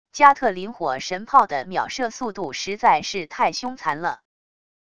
加特林火神炮的秒射速度实在是太凶残了wav音频